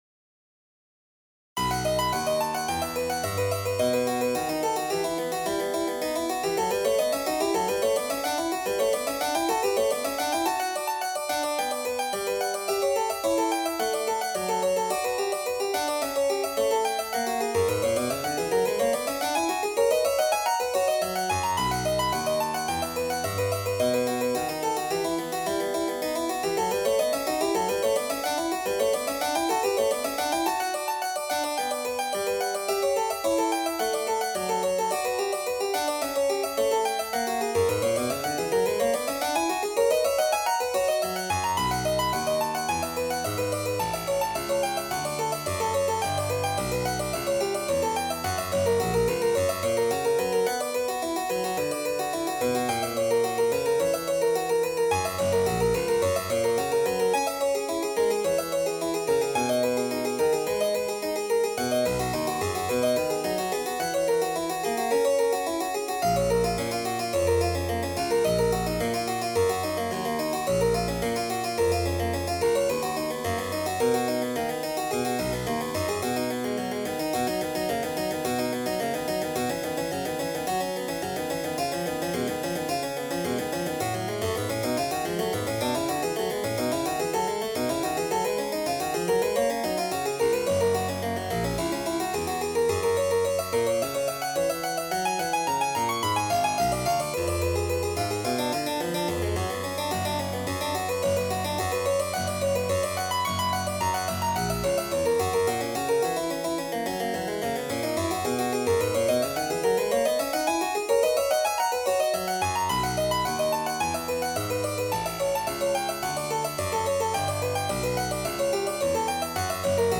This page is devoted to my works for Solo Instruments.
Harpsichord